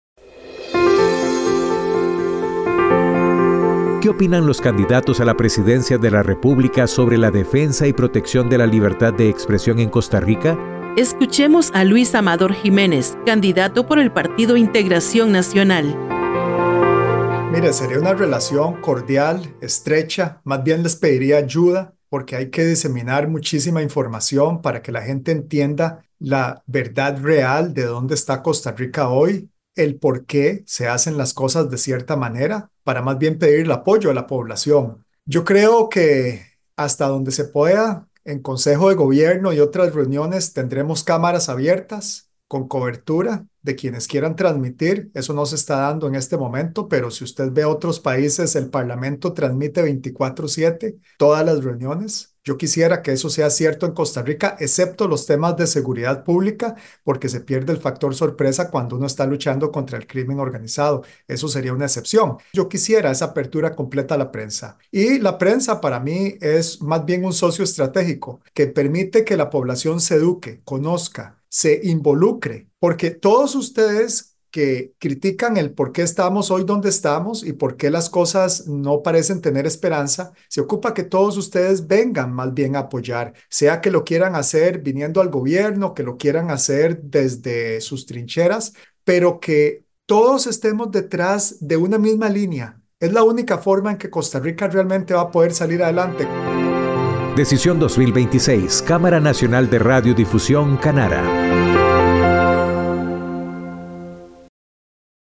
Escuchemos a Luis Amador Jiménez, candidato por el Partido Integración Nacional.